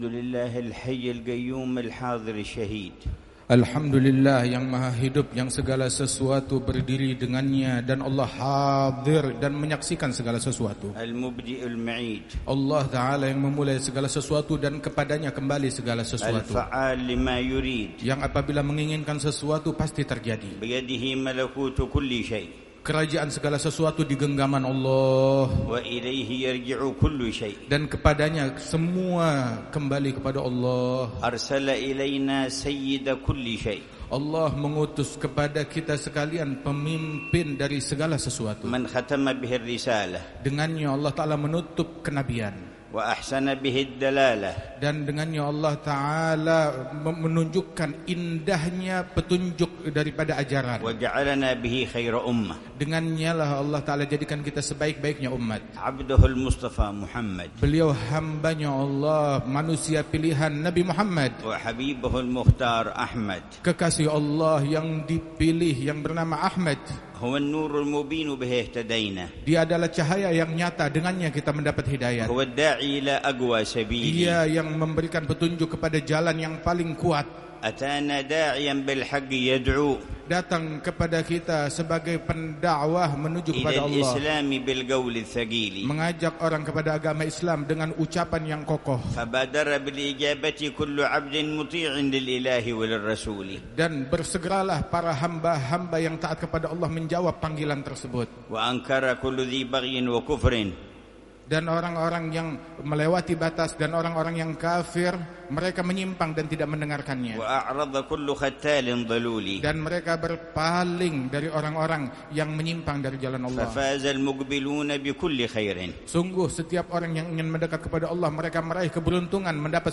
محاضرة العلامة الحبيب عمر بن حفيظ في ذكرى حولية الإمام الحبيب علي بن محمد الحبشي، في مسجد الرياض، بمدينة صولو، مقاطعة جاوة الوسطى، إندونيسيا، ضحى الأحد 20 ربيع الثاني 1447هـ بعنوان: